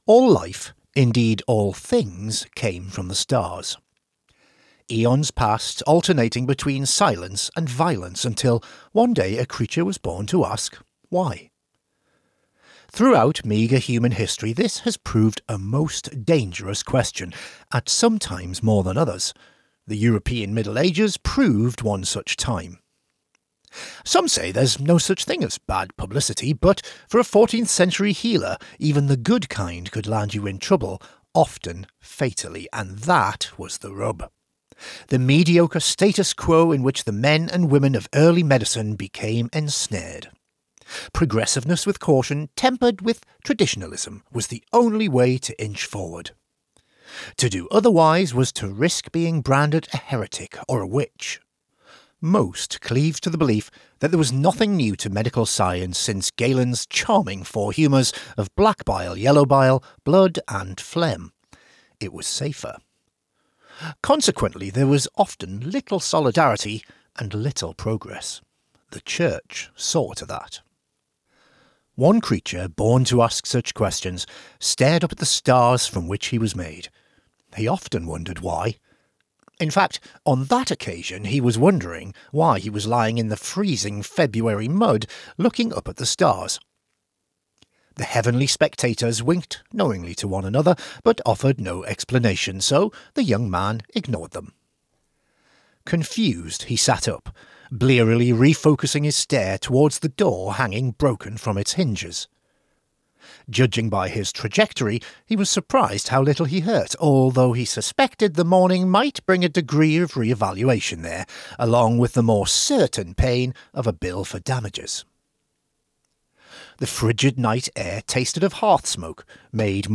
--- audiobook ---